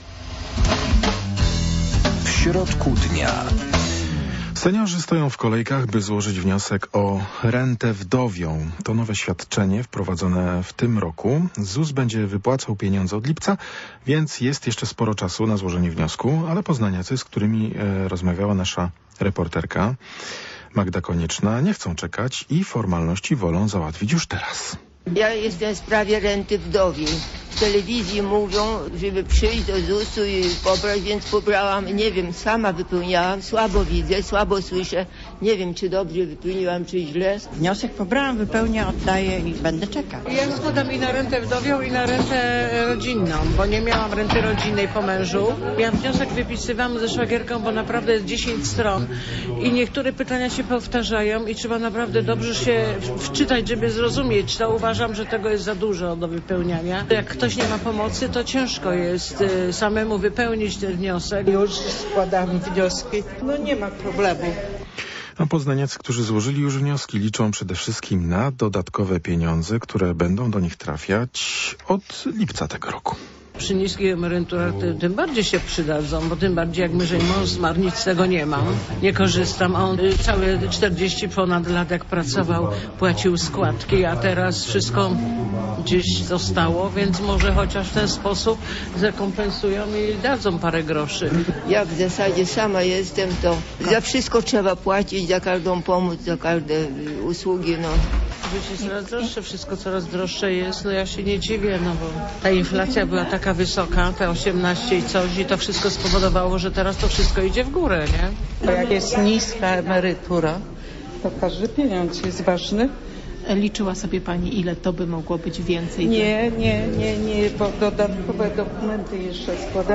W audycji w środku dnia będziemy gościć przedstawicieli Zakładu Ubezpieczeń Społecznych w Poznaniu. Odpowiedzą oni na państwa pytania dotyczące renty wdowiej.